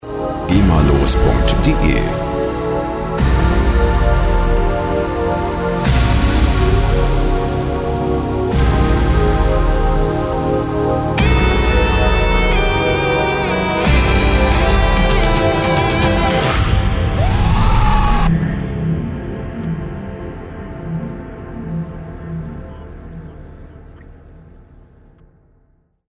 rechtefreie Audio Logos
Musikstil: Fusion
Tempo: 90